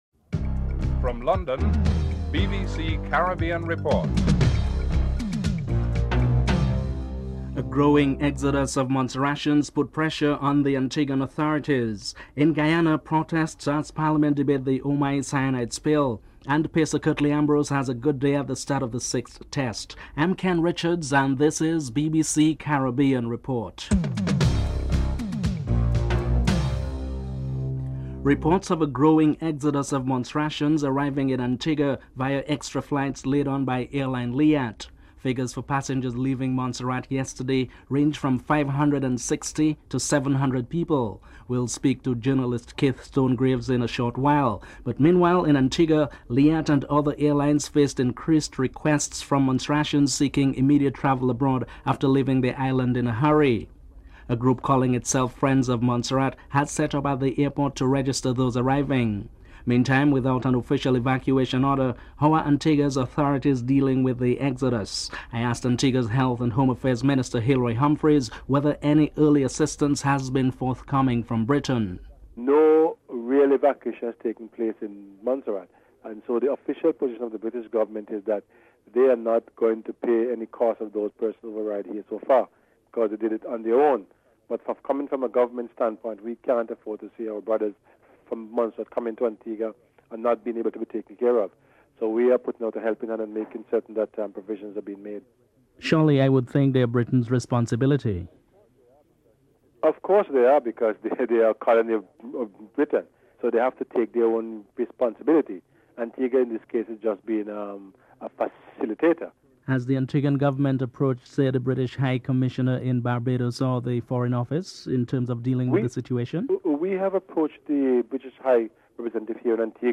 This programme highlights the increasing number of Montserratians arriving in Antigua, although there has been no official evacuation order. Antigua's Health and Home Affairs Minister Hilroy Humphreys comments on whether any early assistance has been forthcoming from Britain.